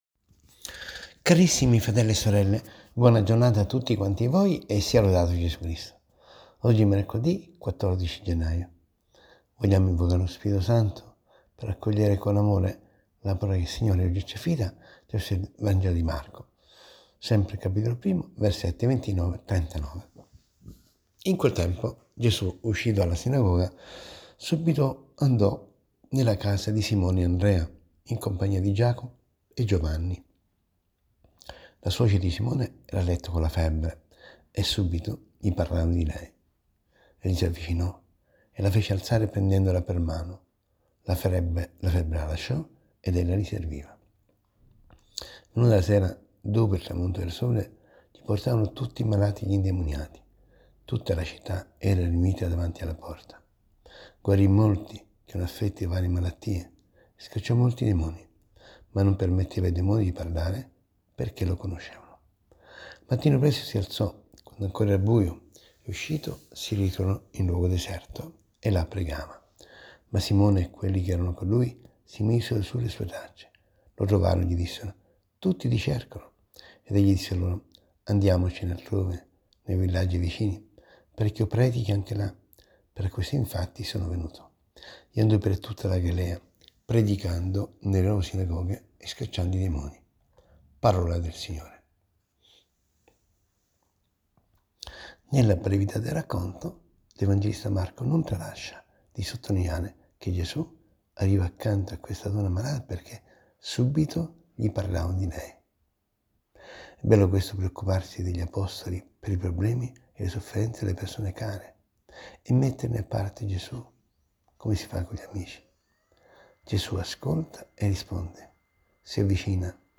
ASCOLTA  RIFLESSIONE SULLA PAROLA DI DIO, - SE L'AUDIO NON PARTE CLICCA QUI